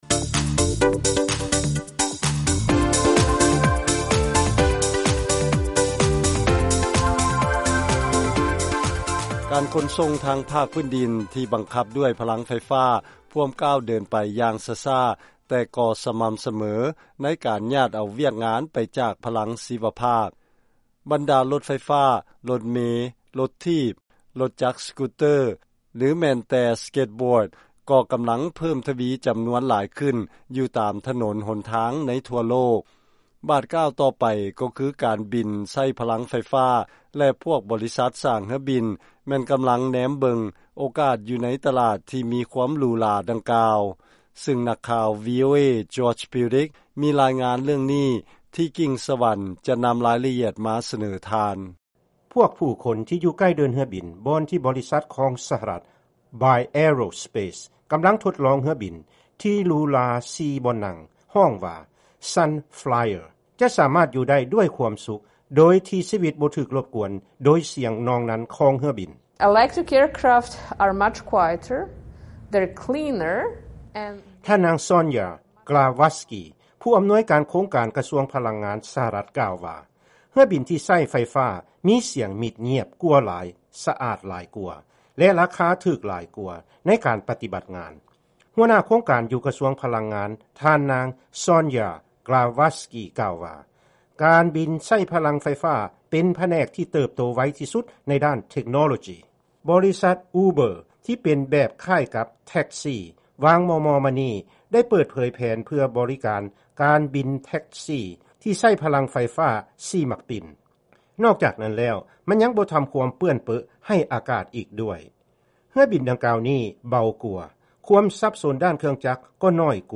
ເຊີນຟັງລາຍງານກ່ຽວກັບ ເຮືອບິນທີ່ໃຊ້ພະລັງໄຟຟ້າ